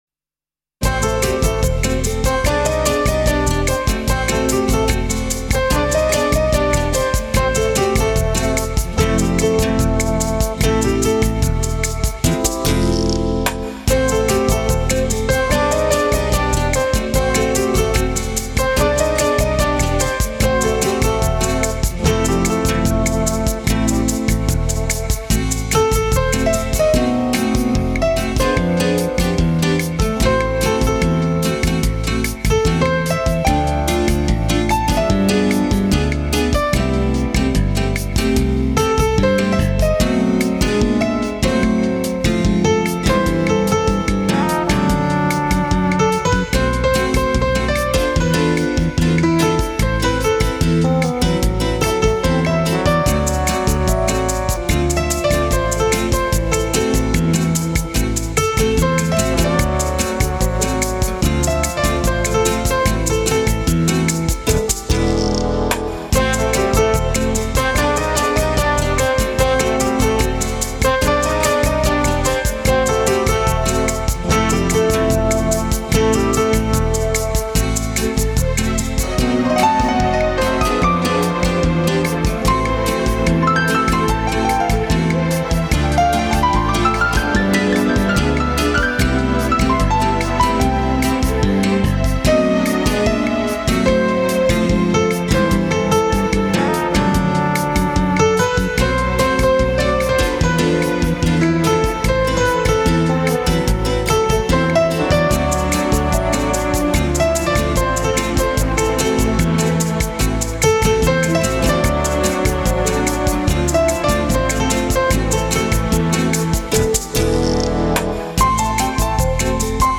只知道这是一个跳舞乐队，见到的唱片无一不是舞曲。